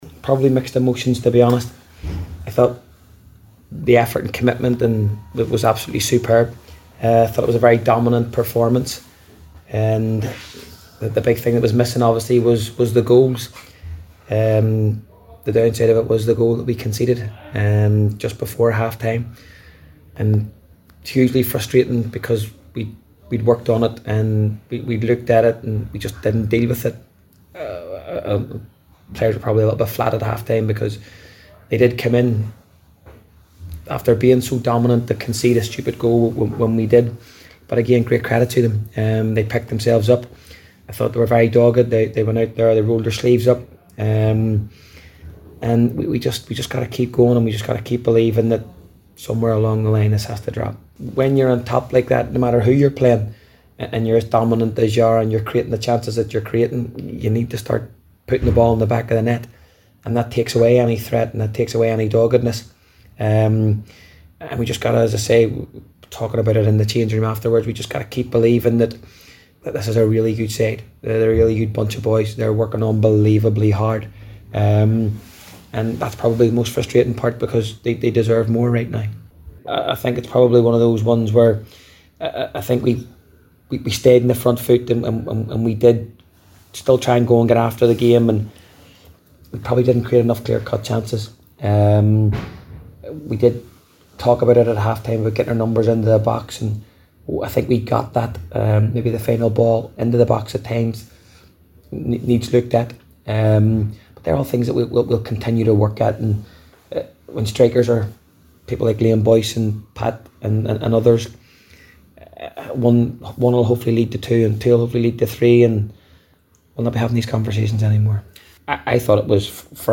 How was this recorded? spoke to the assembled media